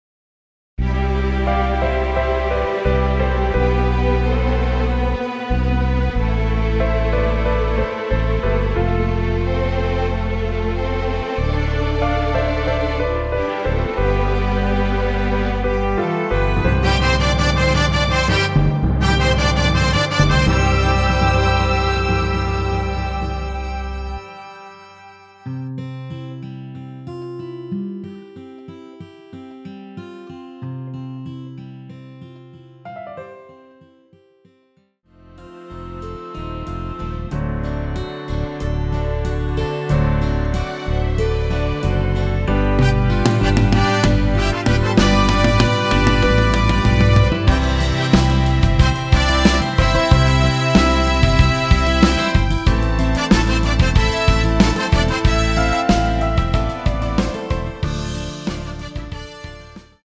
원키에서 (-7)내린 남성분이 부르실수 있는 MR 입니다.(미리듣기 참조)
앞부분30초, 뒷부분30초씩 편집해서 올려 드리고 있습니다.
중간에 음이 끈어지고 다시 나오는 이유는